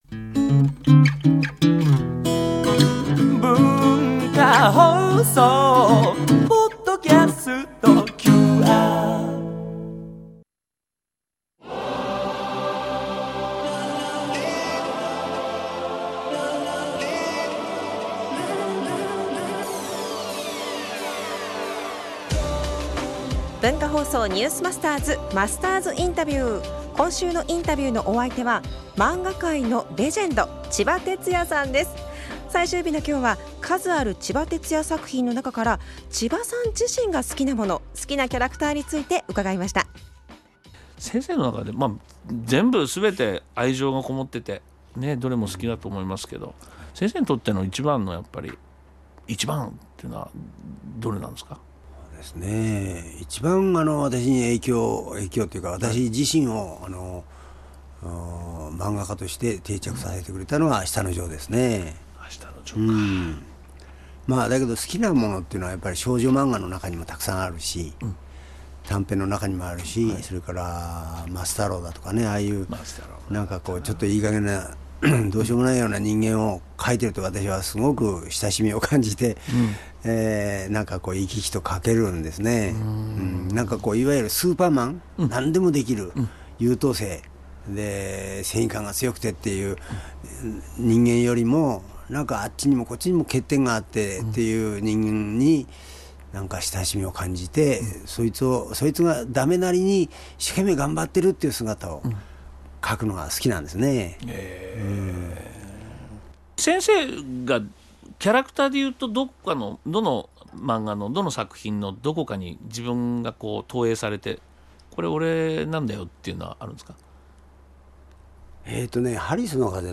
今週のインタビューのお相手は漫画界のレジェンド　ちばてつやさんです。
（月）～（金）AM7：00～9：00　文化放送にて生放送！